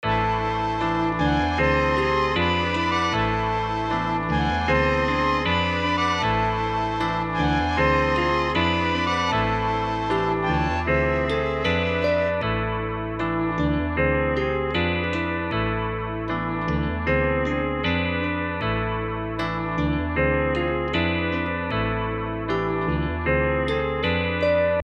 dog_runs_77.5bpm_oz.mp3